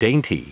Prononciation du mot dainty en anglais (fichier audio)
Prononciation du mot : dainty